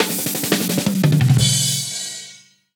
Drum Fills (19).wav